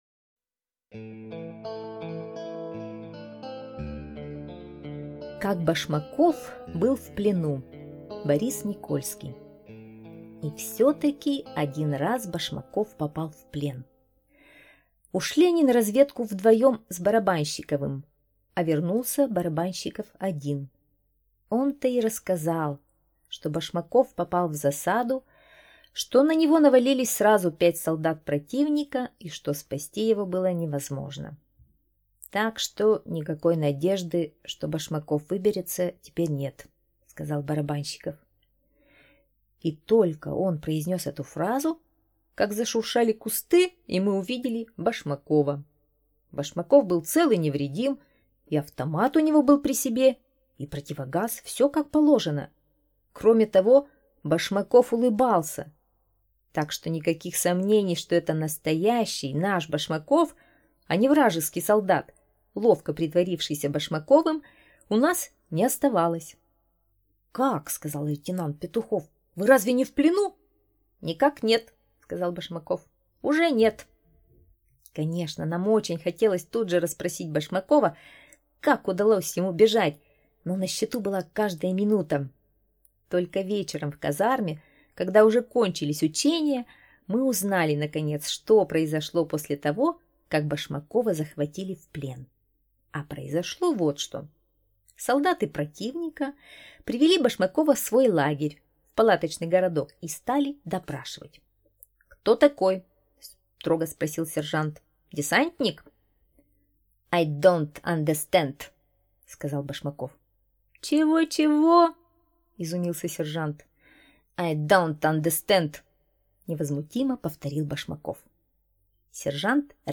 Слушать Как Башмаков был в плену - аудио рассказ Никольского Б. Рассказ о том, как солдат Башмаков ловко придумал выход из сложной ситуации.